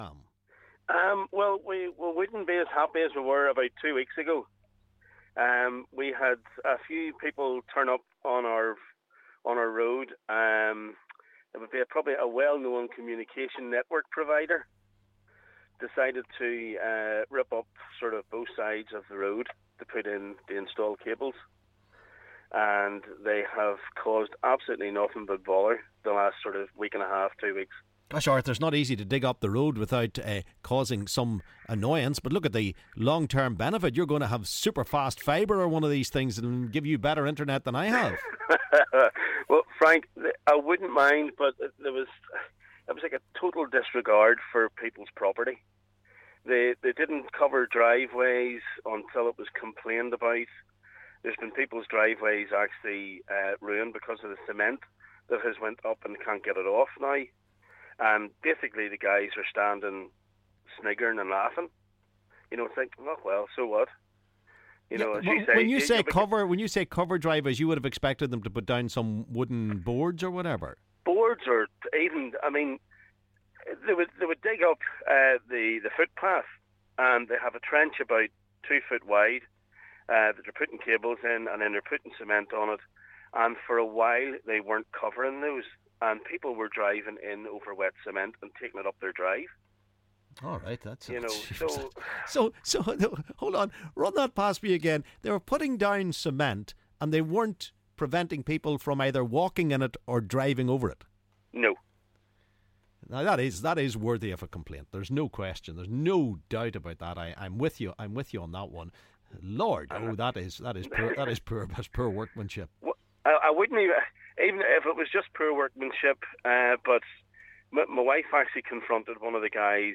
LISTEN ¦ Caller frustrated with roadworks outside his home